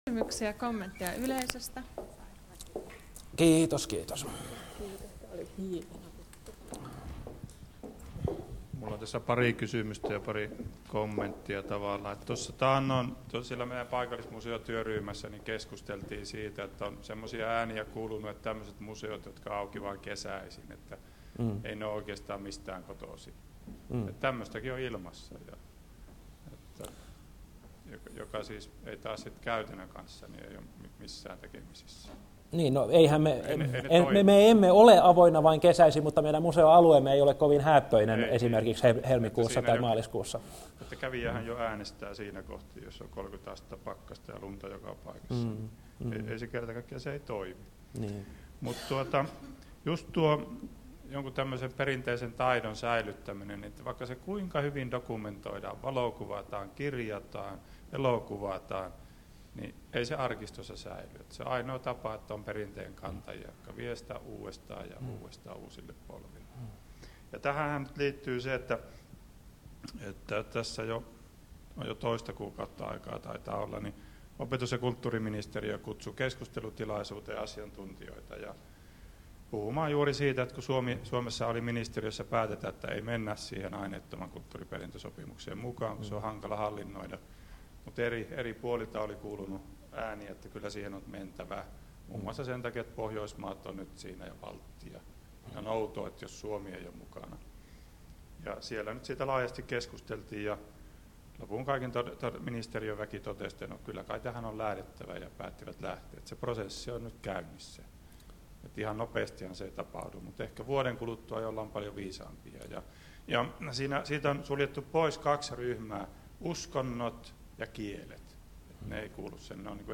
Keskustelu